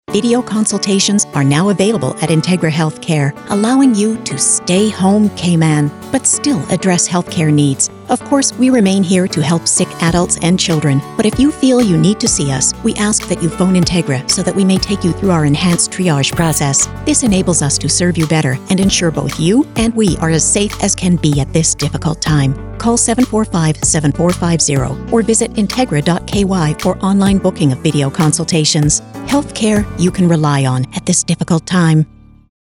Each advert required its own ‘tone’ and inference, and yet all had to conform to a recognisable style.
TONE-SETTING & CHOICE OF VOICEOVER ARTIST